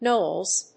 /nolz(米国英語), nəʊlz(英国英語)/